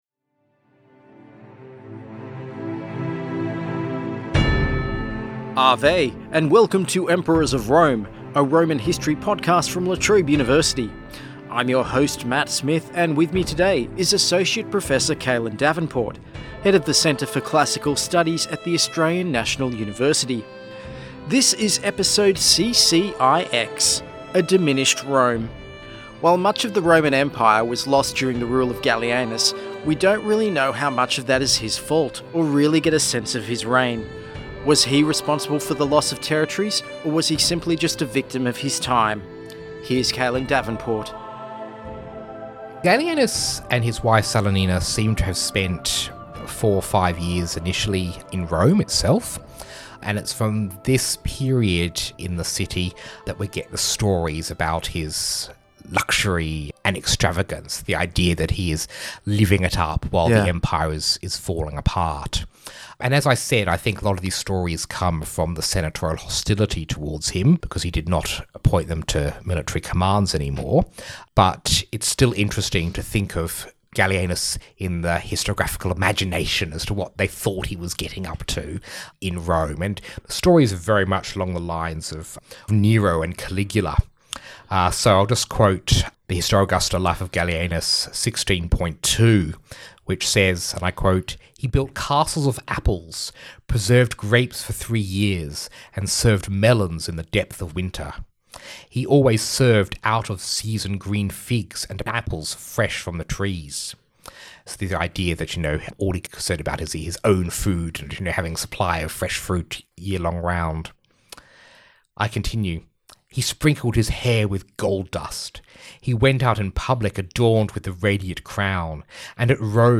Guest: Associate Professor